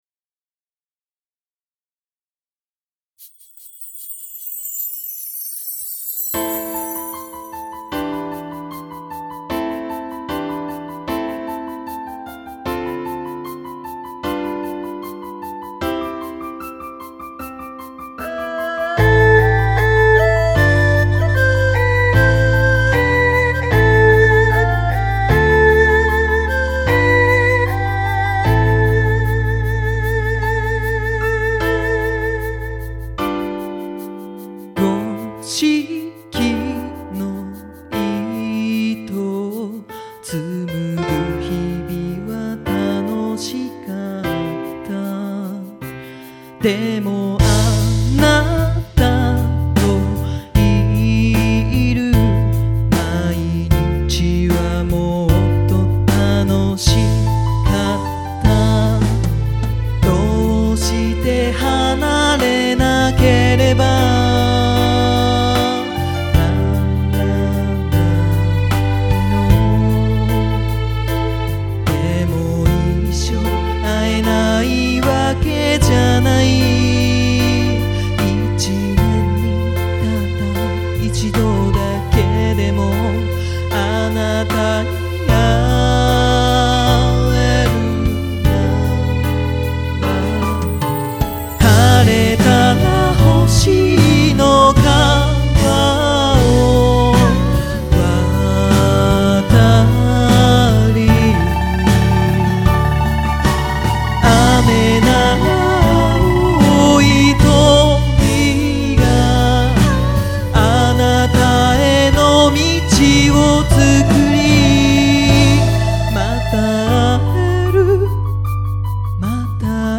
「歌モノ部門」最優秀作品です。
・アコースティックギター